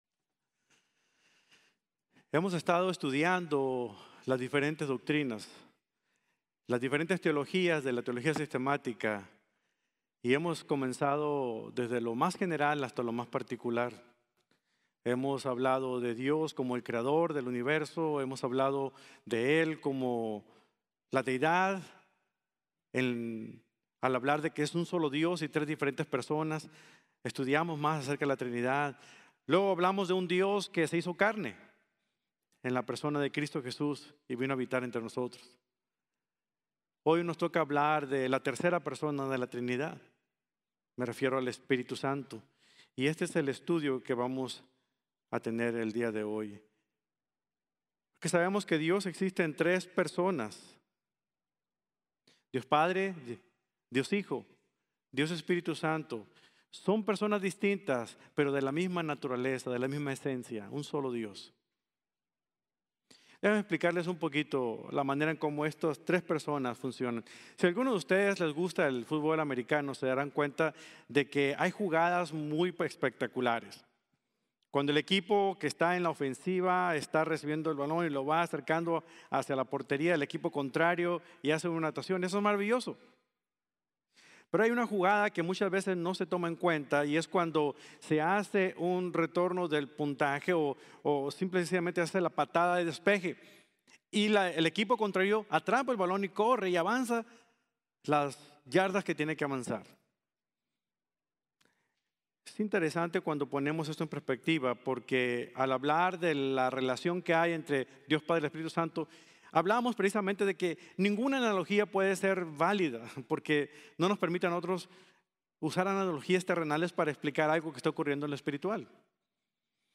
El Espíritu Santo y Yo | Sermón | Iglesia Bíblica de la Gracia